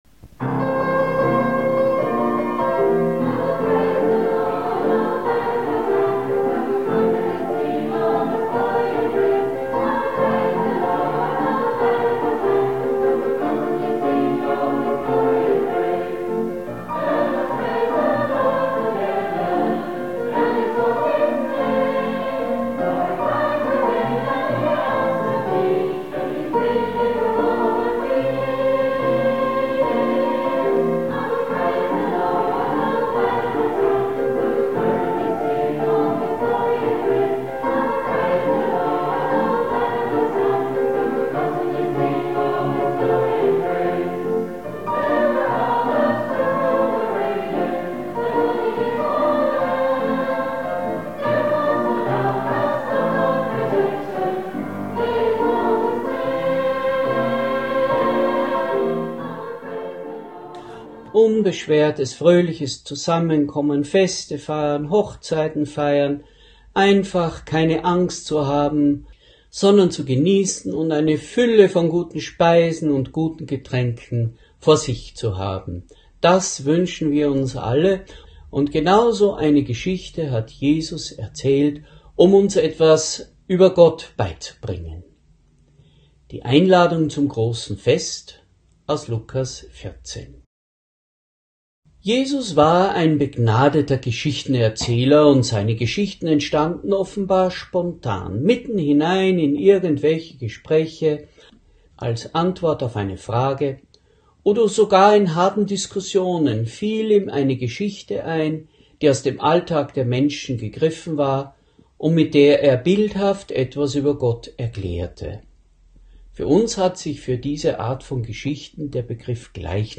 Predigt | NT03 Lukas 14,13-24 Das große Gastmahl